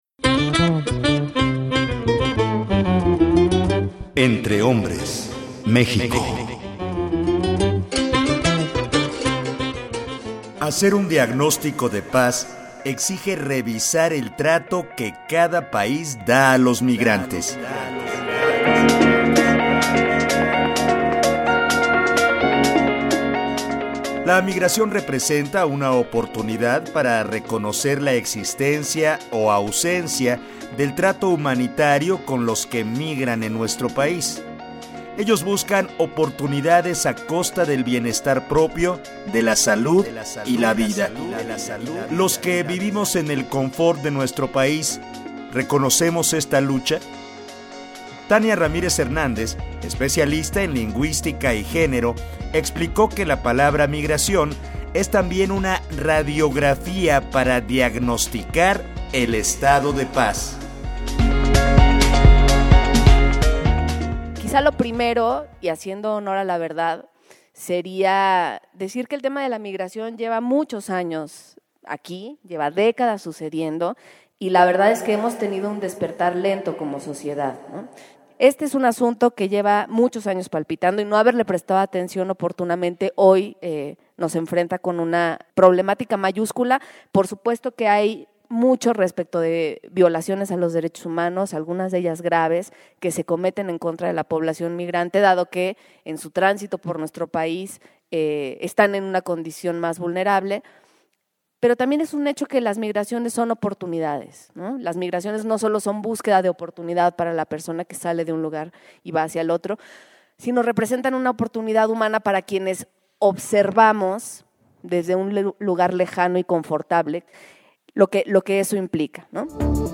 Durante su participación en el Encuentro Internacional por una Cultura de Paz, la Dra. en Lingüística, Tania Ramírez, hizo especial énfasis en los migrantes trans.